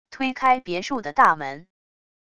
推开别墅的大门wav音频